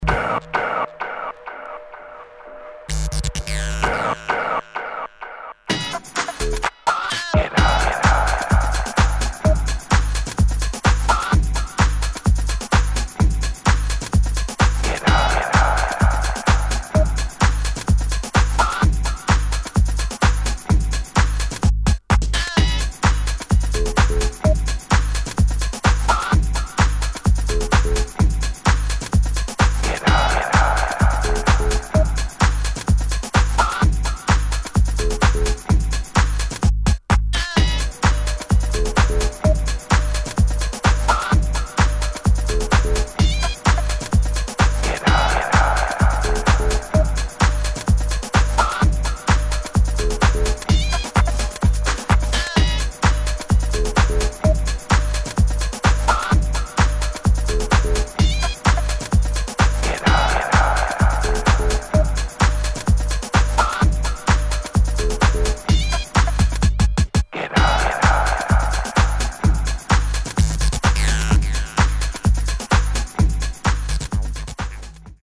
[ HOUSE / BASS / TECHNO ]